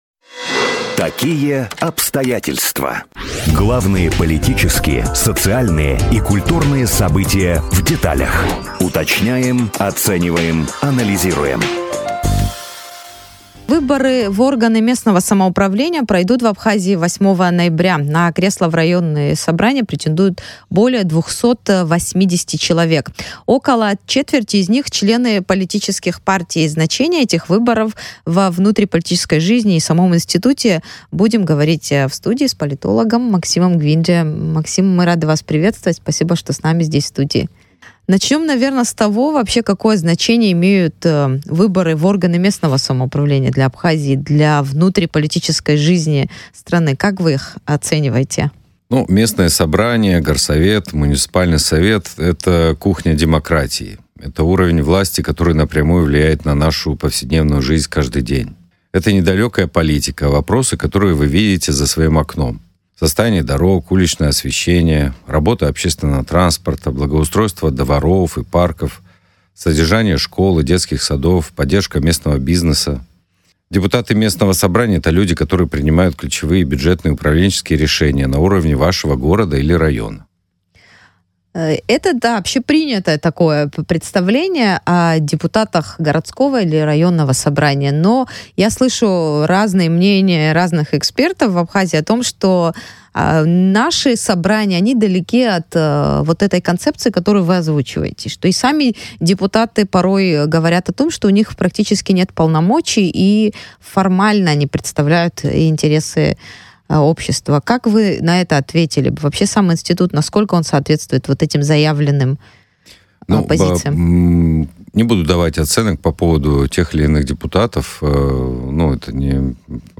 Выборы в местное самоуправление. Интервью с политологом о роли депутатов